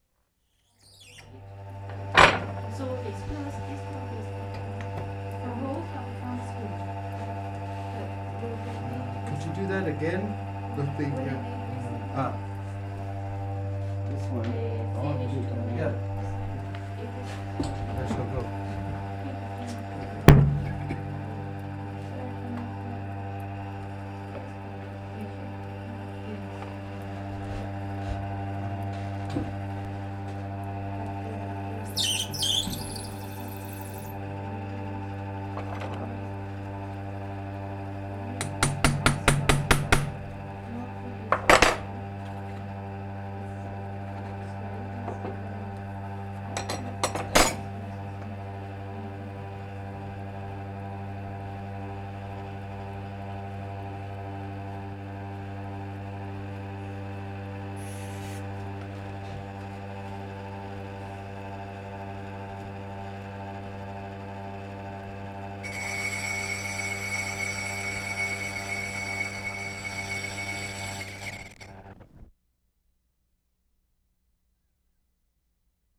WORLD SOUNDSCAPE PROJECT TAPE LIBRARY
GLASS ENGRAVING